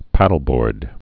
(pădl-bôrd)